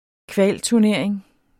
Udtale [ ˈkvaˀl- ]